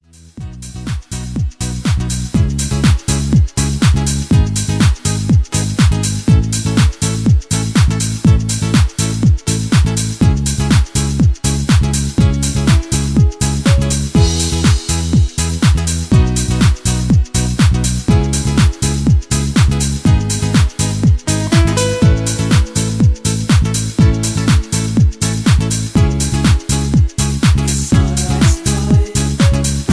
Backing Tracks for Professional Singers.